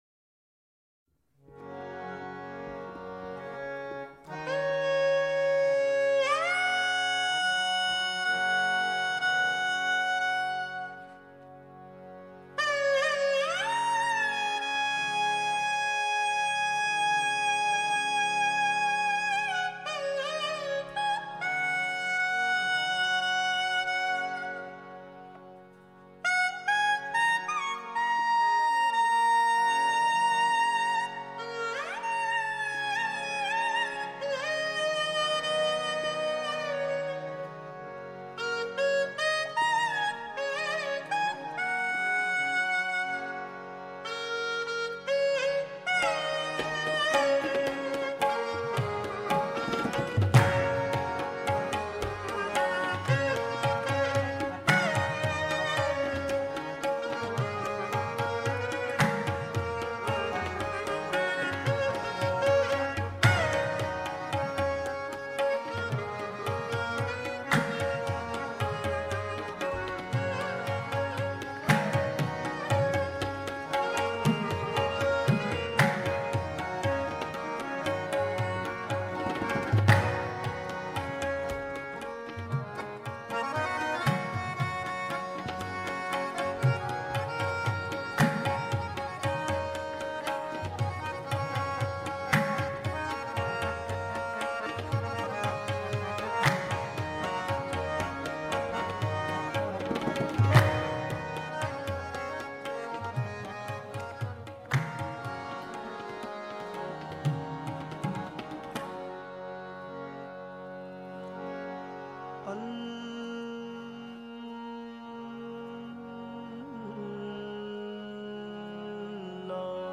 Qawwali Download (Right click + save target as…